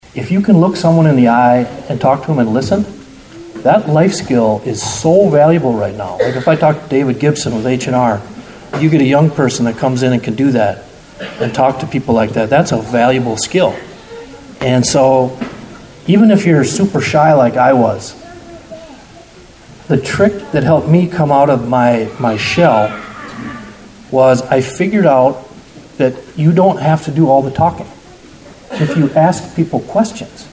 click to download audioHe also offered advice for the young people that were on hand for the dinner.